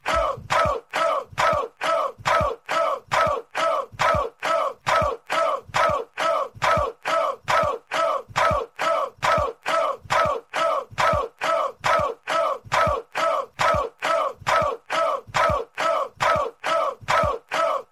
Tiếng Vỗ tay, hô Go go go… sound effect
Thể loại: Âm thanh meme Việt Nam
Âm thanh này mô phỏng cảm giác khán giả đang hò reo, cổ vũ nhiệt tình... Hiệu ứng bao gồm tiếng vỗ tay, tiếng reo hò, tiếng hô hào liên tục, mang lại cảm giác chân thực, tăng độ kịch tính...
tieng-vo-tay-ho-go-go-go-sound-effect-www_tiengdong_com.mp3